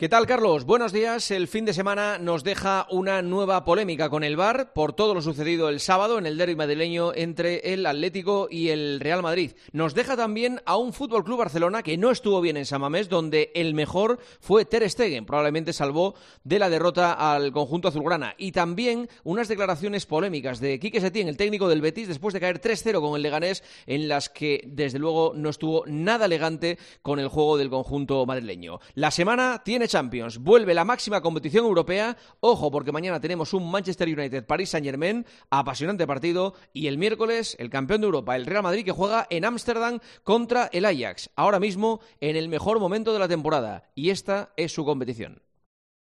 El comentario de Juanma Castaño